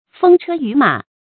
風車雨馬 注音： ㄈㄥ ㄔㄜ ㄧㄩˇ ㄇㄚˇ 讀音讀法： 意思解釋： 指神靈的車馬。亦用以比喻迅疾、快速。